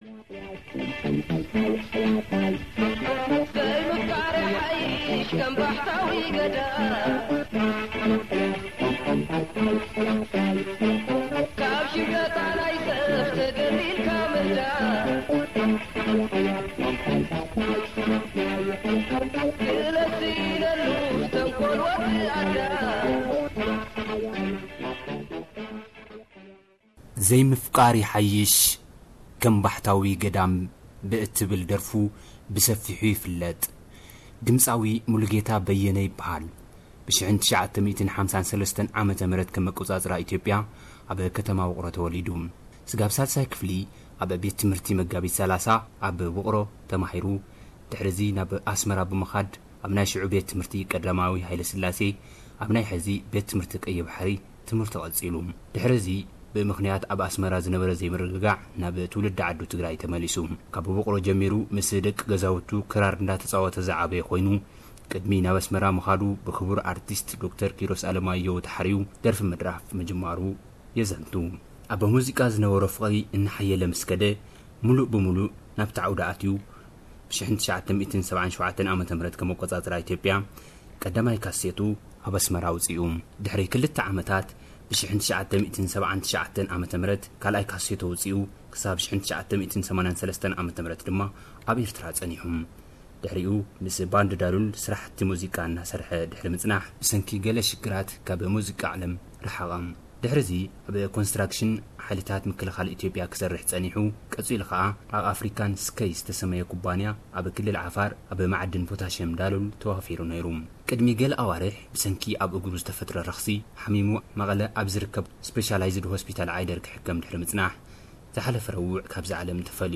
ወናምን ገዲምን ስነ ጥበባዊ ሙሉ ጌታ በየነ አብ እገሩ ብዘጋጠሞ ኩነታት ምርካስ ዓሪፉ፡፡ ዝሓለፈ ረቡዕ 5 ሚያዝያ 2008 ዓ.ም ከም አቆፃፅራ ኢትዮጵያ አብ ትውልዲ ዓዱ ትግራይ ከተማ ውቅሮ ሐመድ ድበኡ ተፈፂሙ፡፡ ቅደሚ ገለ አዋርሕ አብ ዝገበሮ ቃለ መሕትት ብዛዕባ ስራሕቱን ኣብ ኢትዮጵያን ኤርትራን ዝነበሮ ተዘክሮታትን ቀፃሊ ሰሙን አብ ኤስ ቢ ኤስ ስምዕዎ፡፡